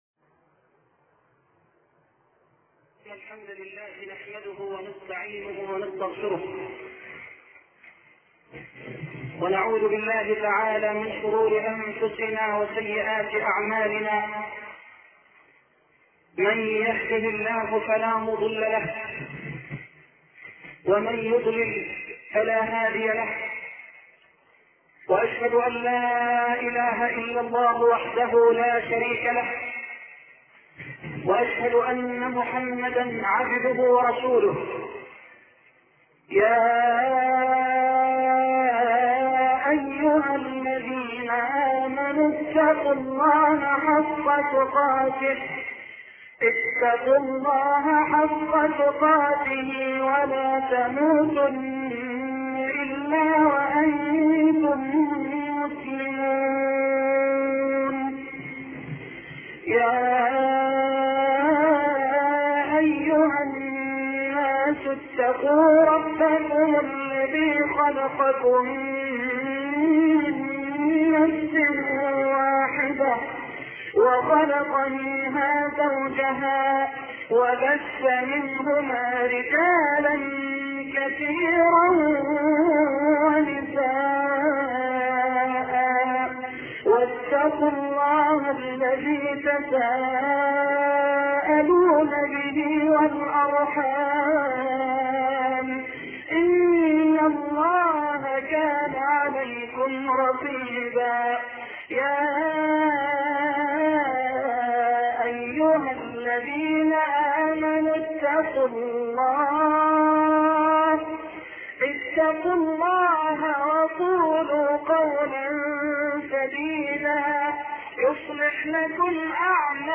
شبكة المعرفة الإسلامية | الدروس | قدر النبي عند الله |محمد حسان قدر النبي عند الله محمد حسان  الاشتراك  لدي مشكلة  دخول 7/8/1438 قدر النبي عند الله قدر النبي عند الله Loading the player...